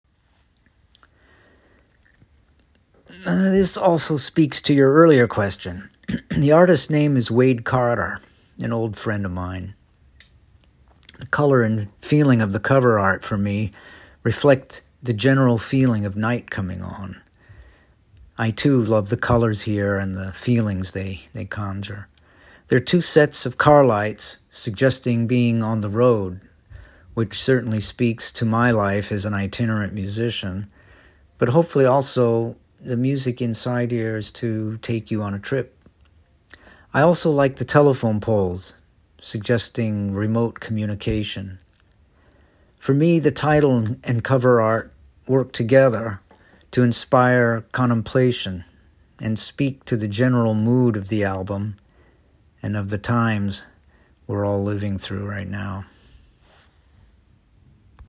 Über das ECM-Hauptquartier in Gräfelfing bekam ich heute die Antworten auf meine neun Fragen zu seinem am 27. August erscheinenden Solo-Bass-Album „Overpass“ als audio files zugestellt, hörte sie mir an, und war schlichtweg begeistert: sachlich, ruhig und beseelt zugleich, jede Antwort.
Alle Antworten (bis auf den einen hier, in dem Marc Johnson etwas zum Cover errzählt) werden auf dem Blog bis Ende August anzuhören sein, aber zuerst gesendet im Deutschlandfunk, in den JazzFacts und den Klanghorizonten.